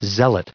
1559_zealot.ogg